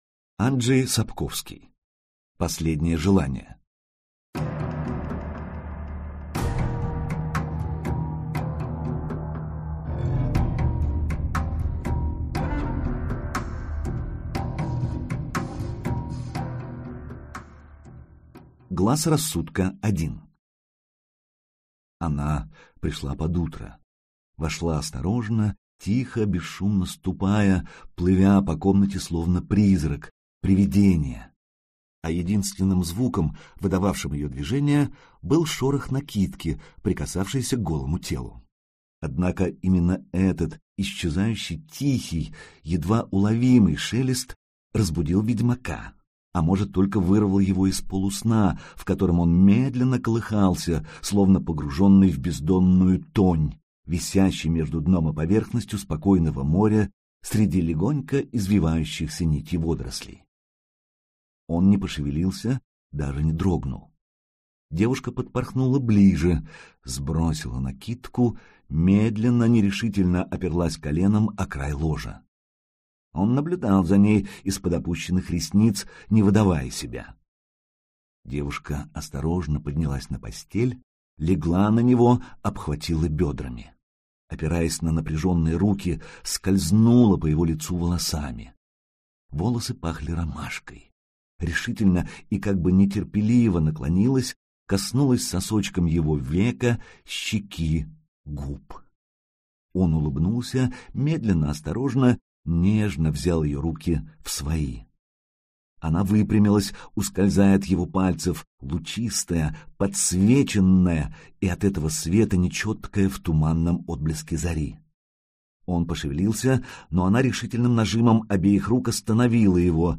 Аудиокнига Ведьмак | Библиотека аудиокниг